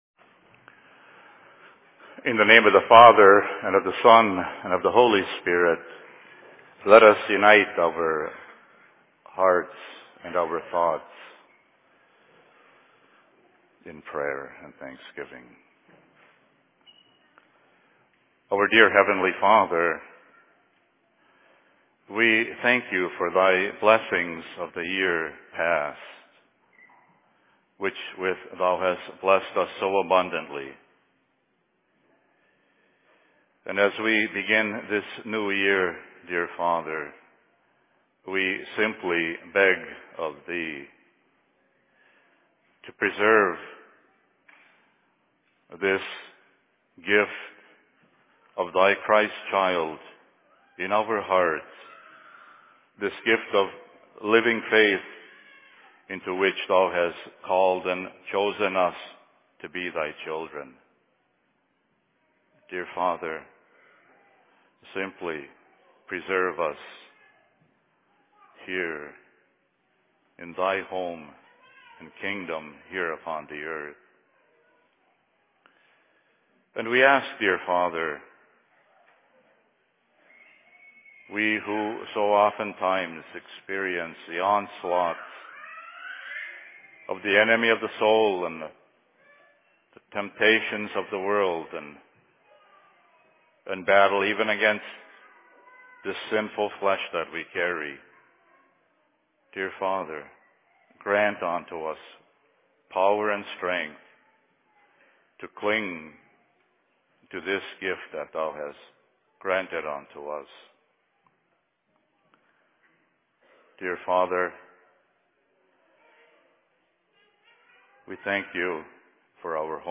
Sermon in Cokato 03.01.2016